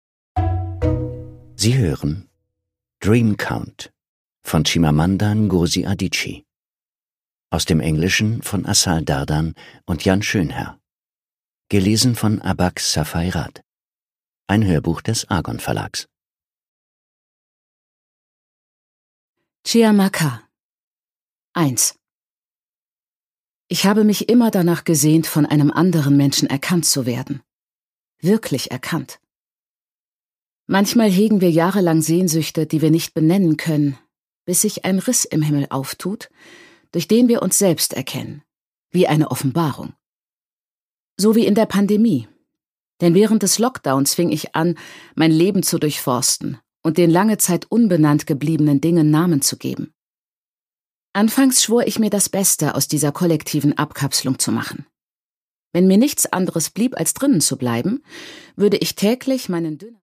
Chimamanda Ngozi Adichie: Dream Count (Ungekürzte Lesung)
Produkttyp: Hörbuch-Download